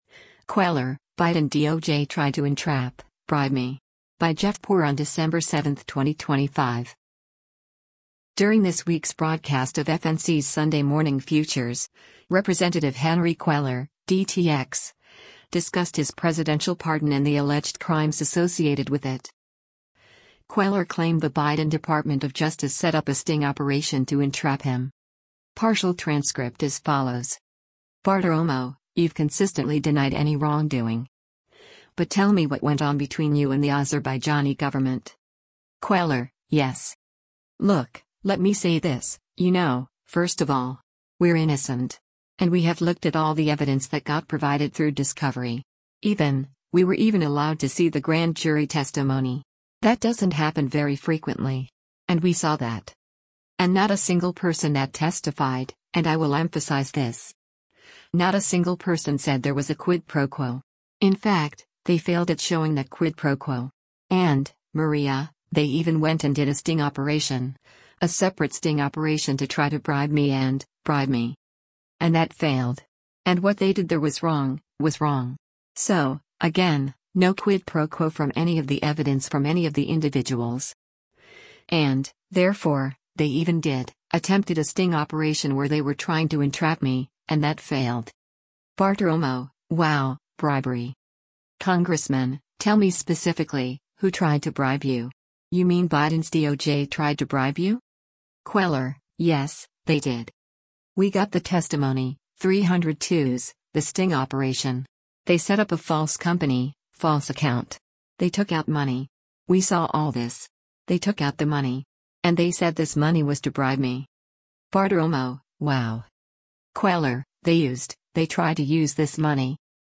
During this week’s broadcast of FNC’s “Sunday Morning Futures,” Rep. Henry Cuellar (D-TX) discussed his presidential pardon and the alleged crimes associated with it.